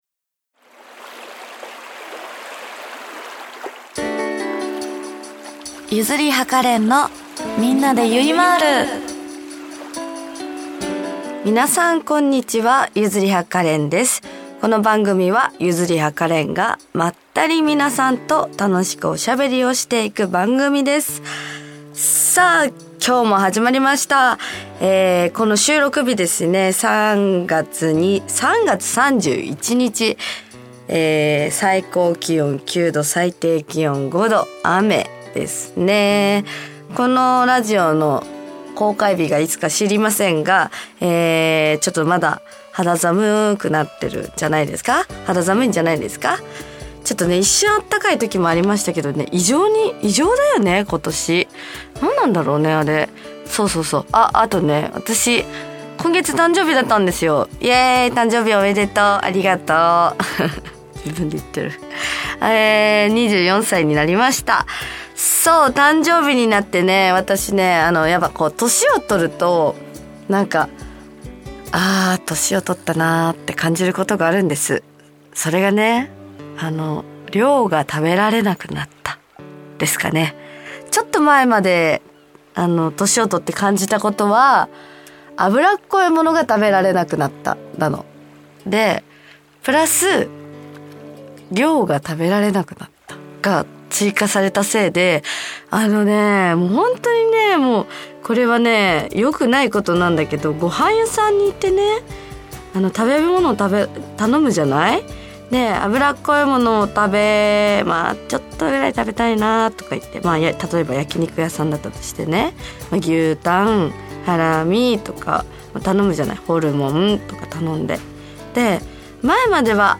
極力カットせずにお送りいたします♪